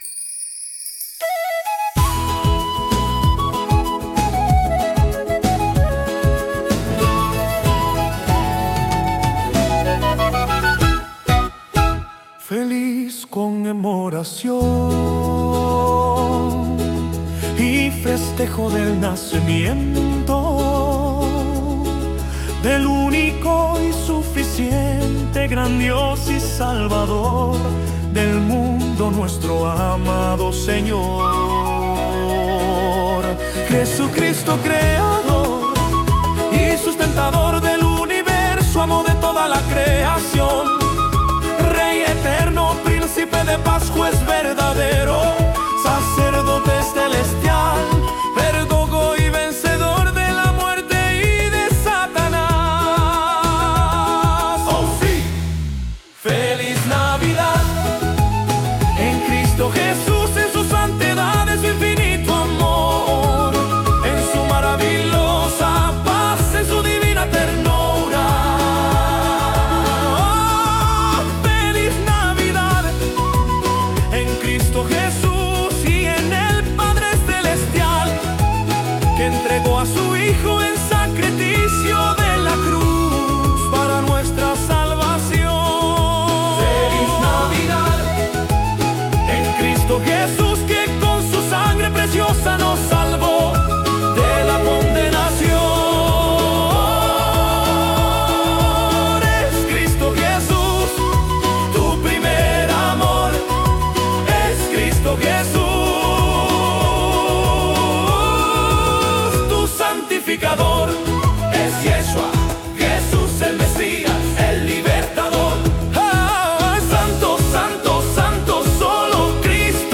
Genre Gospel